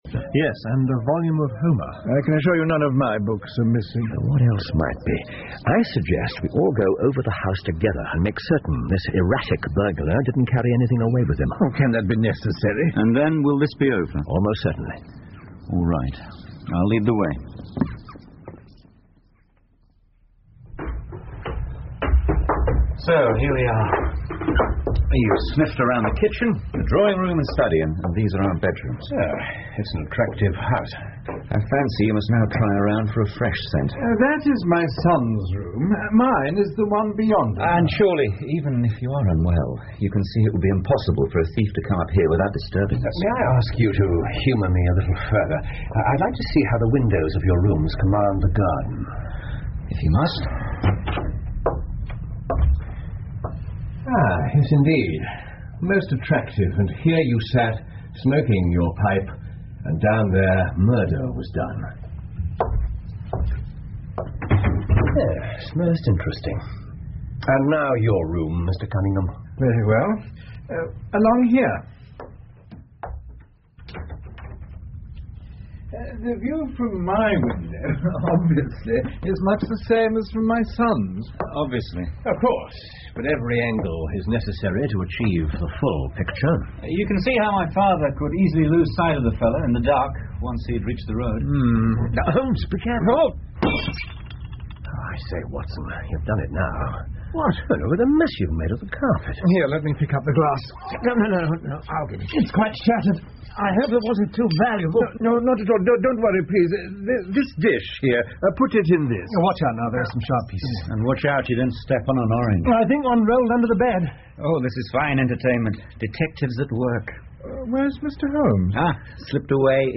福尔摩斯广播剧 The Reigate Squires 7 听力文件下载—在线英语听力室